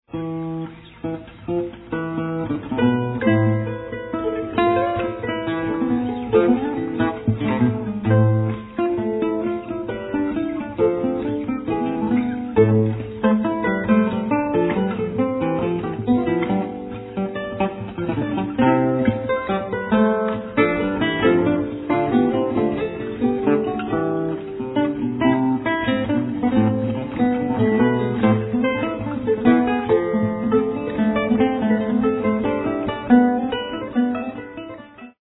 in 3 parts (textless, playable by instruments